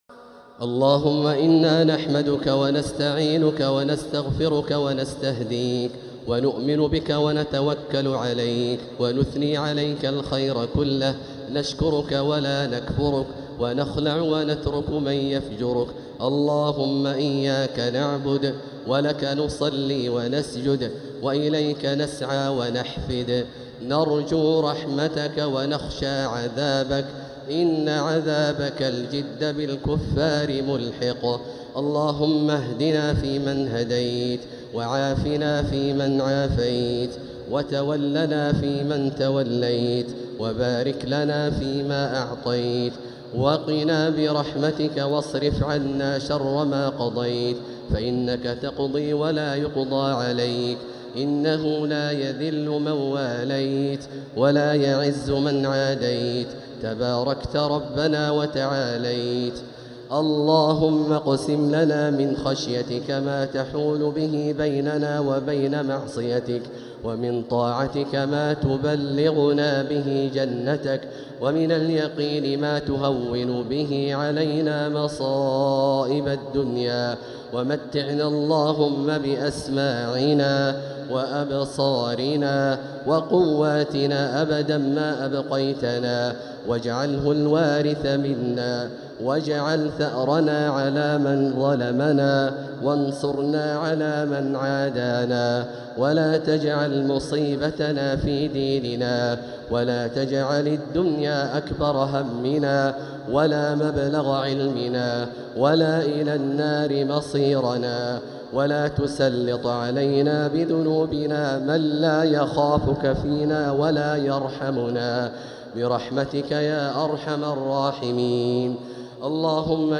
دعاء القنوت ليلة 5 رمضان 1447هـ > تراويح 1447هـ > التراويح - تلاوات عبدالله الجهني